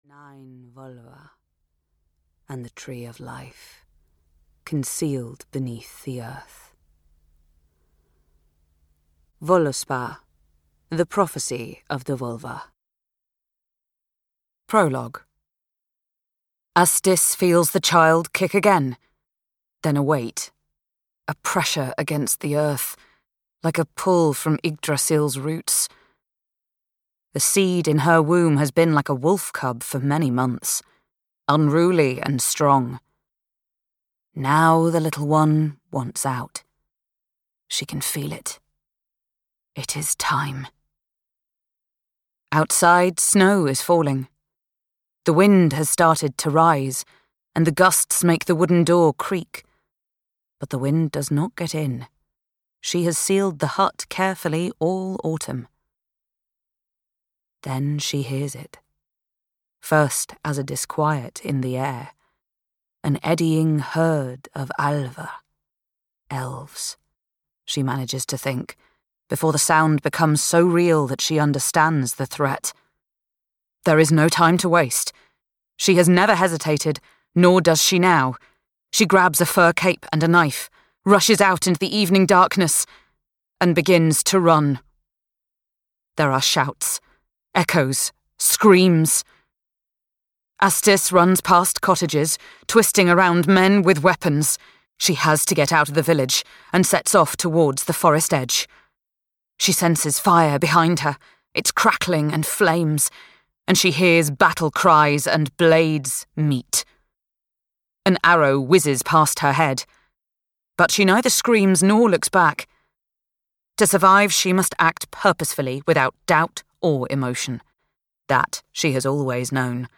The Seer of Midgard (EN) audiokniha
Audiobook The Seer of Midgard, written by A. M. Vedsø Olesen.
Ukázka z knihy